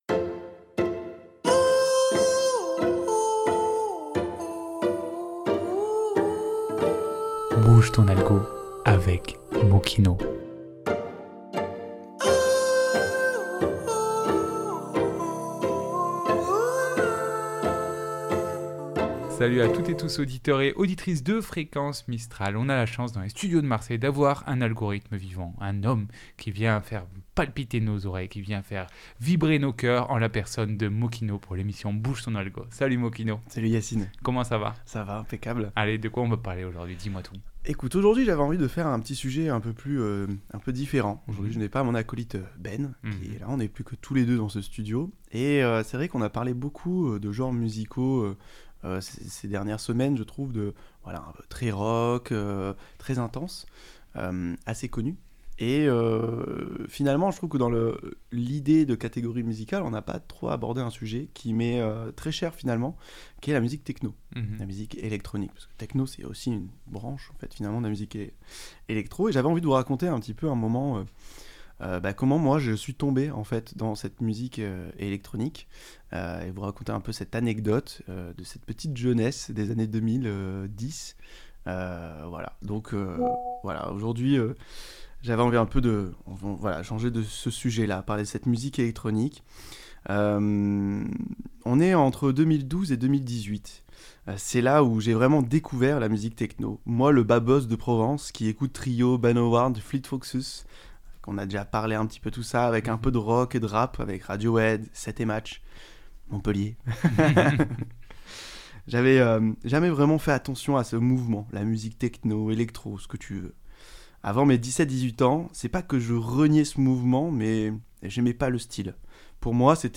émission musicale spéciale